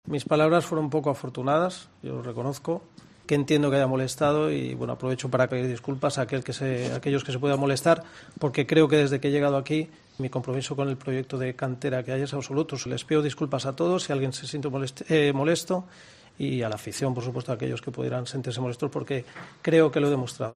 Por eso, aprovecho para aclararla y pedir disculpas", indicó el técnico valenciano en rueda de prensa.